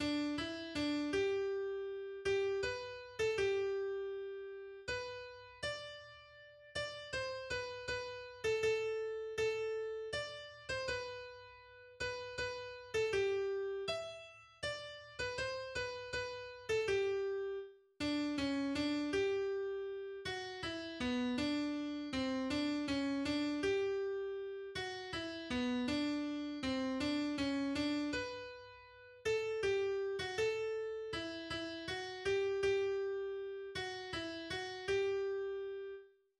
romantisches Heimatlied